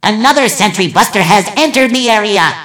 mvm_sentry_buster_alerts07.mp3